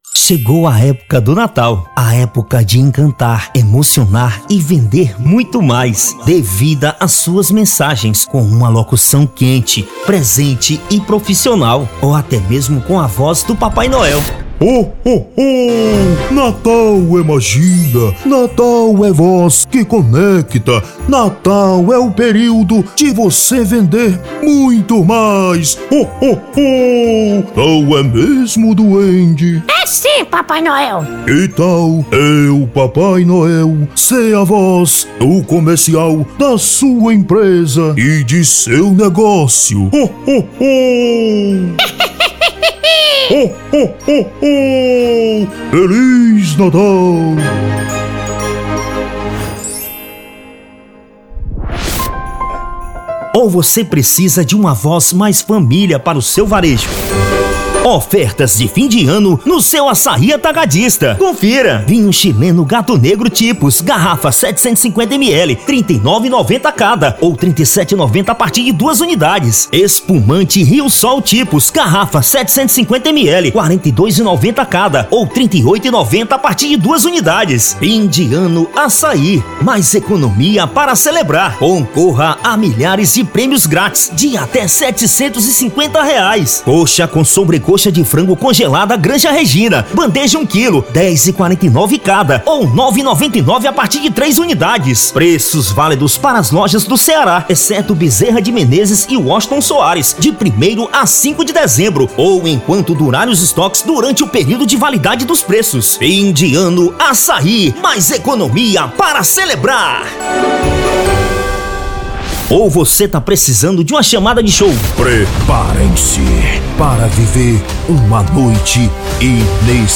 Estilo(s):
Impacto
Animada
Caricata